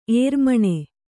♪ ērmaṇe